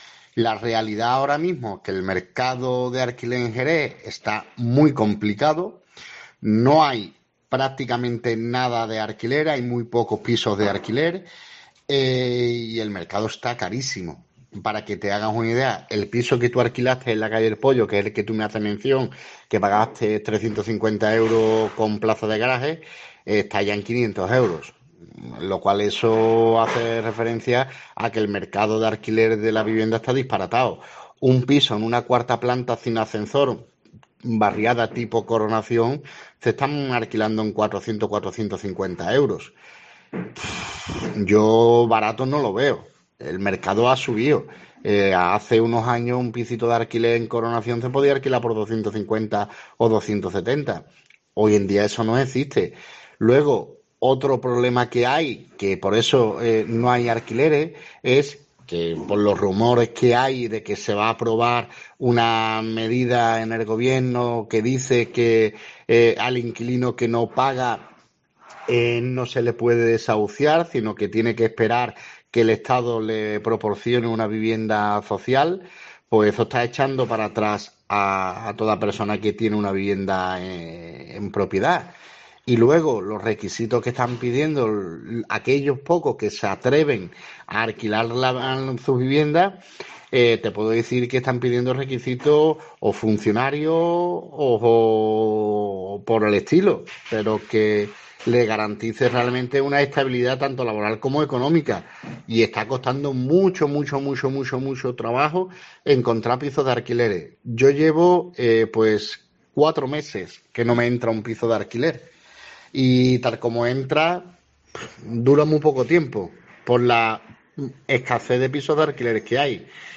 agente inmobiliario